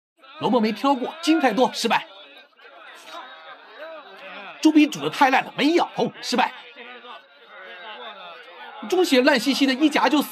ドラマチックなストーリーテリングのナレーション
テキスト読み上げ
映画のようなナレーション
当社のAI音声は、意図的なペース配分、意味のある間、親密な息遣いで緊張感を形成し、プロのナレーターが聴衆をシーンに引き込むために使用するリズムを捉えます。